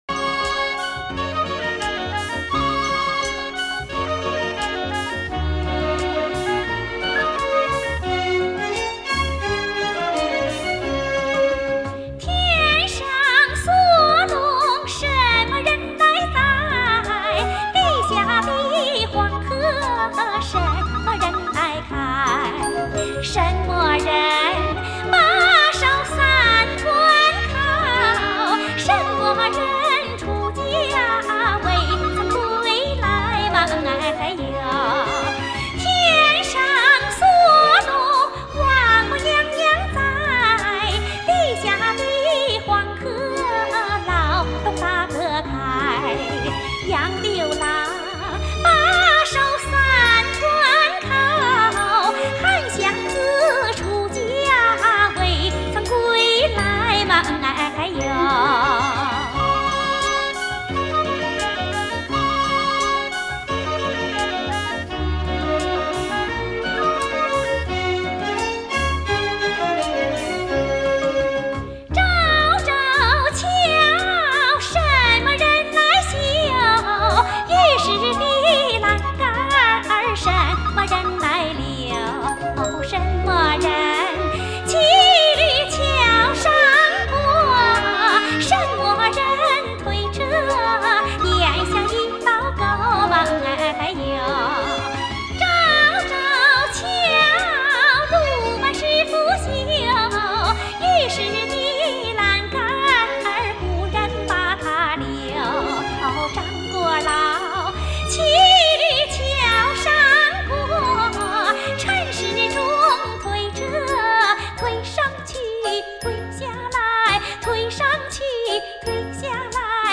[light]小 放 牛[/light] 河北民歌 演唱 任桂珍 天上锁龙什么人来栽？